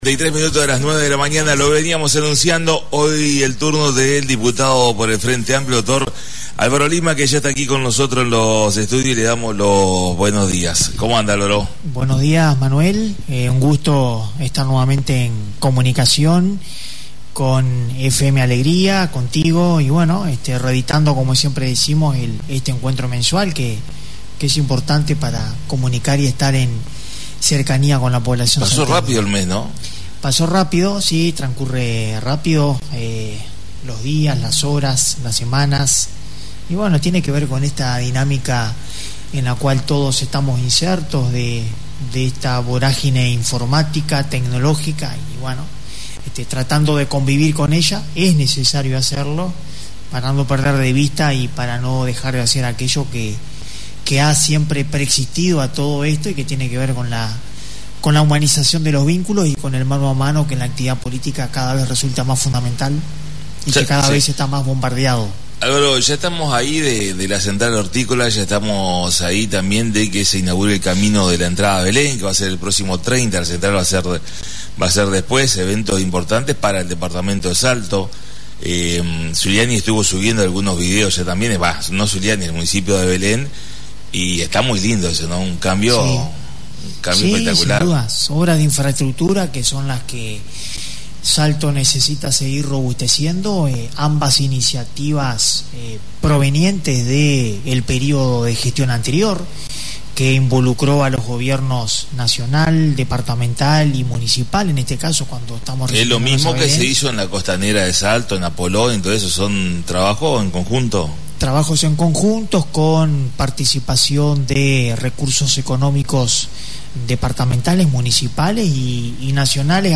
La visita a la Radio del Diputado por el Frente Amplio Dr Álvaro Lima y una puesta al día como es costumbre